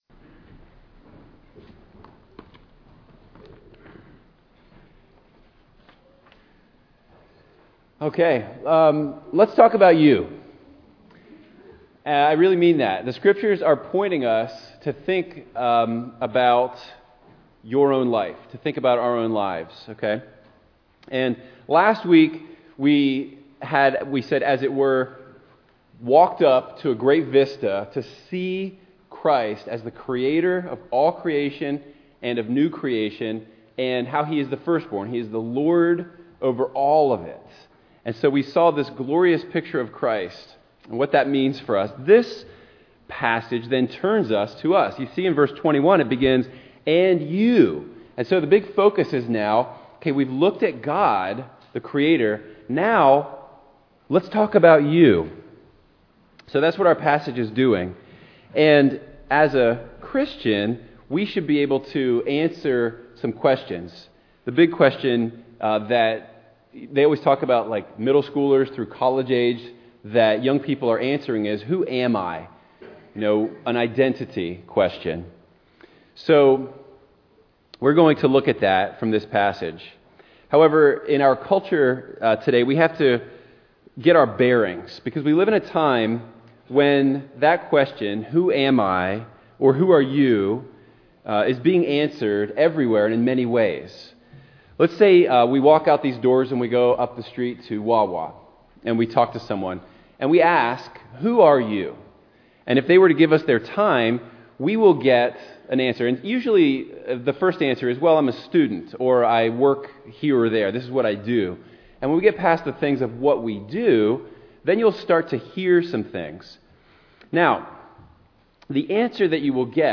2022 Sermons